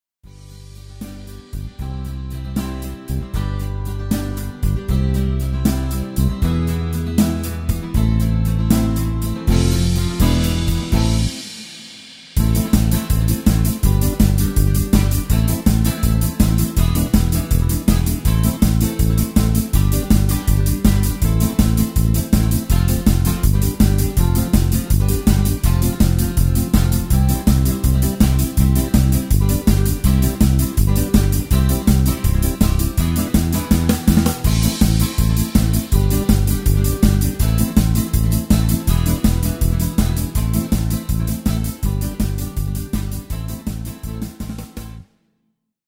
version live